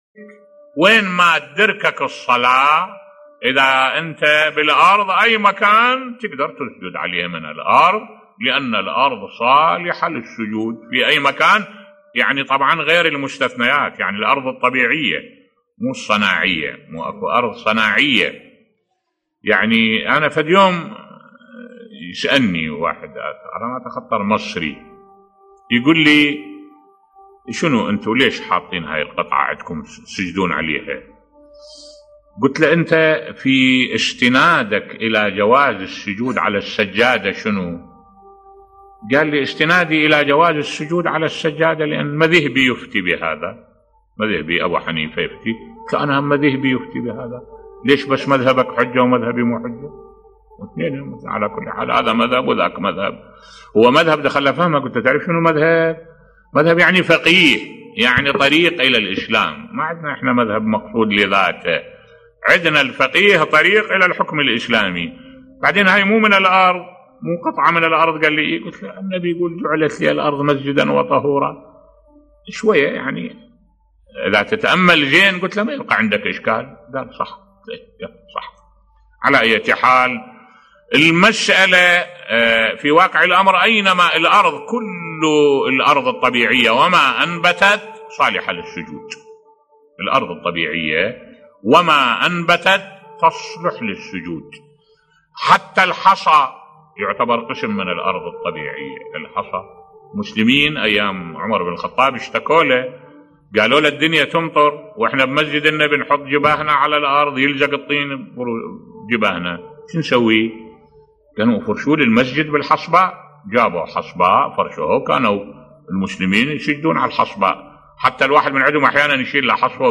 ملف صوتی لماذا يسجد الشيعة على التراب بصوت الشيخ الدكتور أحمد الوائلي